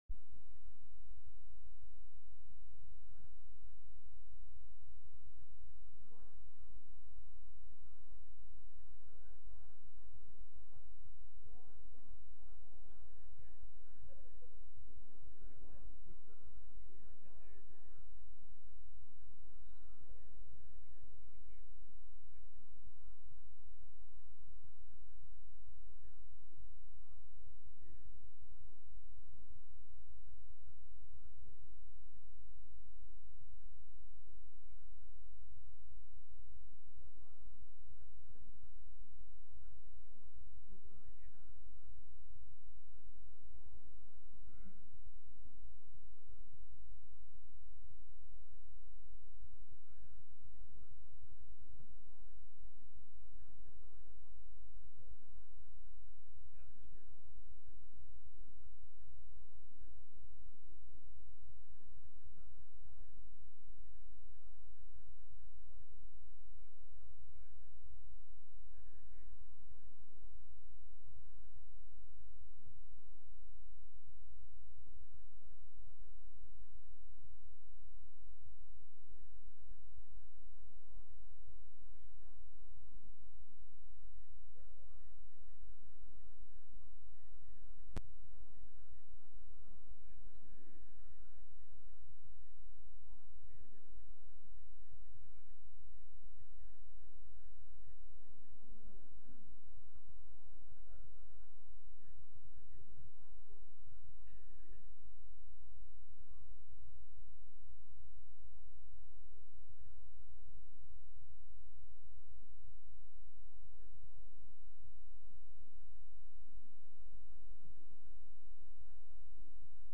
From Series: "Sunday Worship"
Sunday-Service-7-3-22.mp3